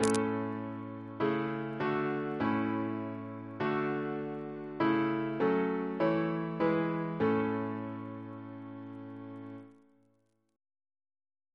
Single chant in A Composer: Samuel Stephens (b.1836) Reference psalters: ACB: 104